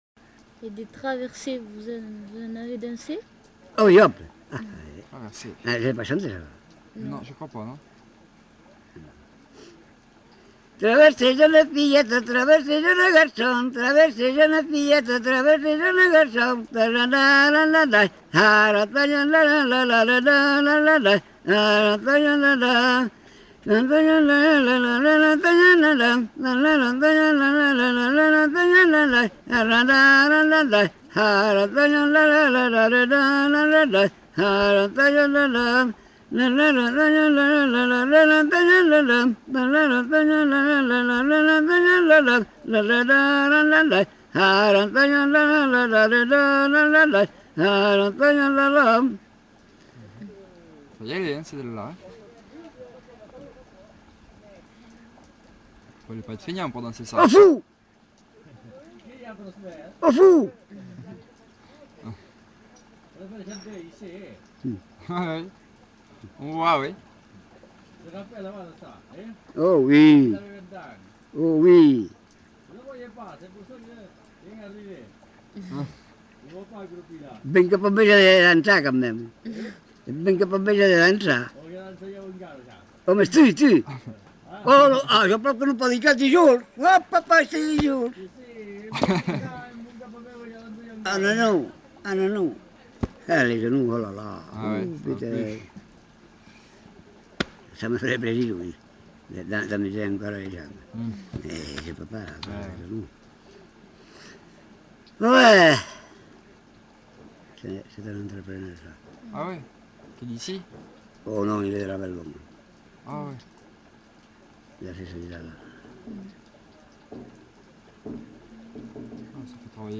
Aire culturelle : Couserans
Genre : chant
Effectif : 1
Type de voix : voix d'homme
Production du son : fredonné ; chanté
Danse : traversée ; castanha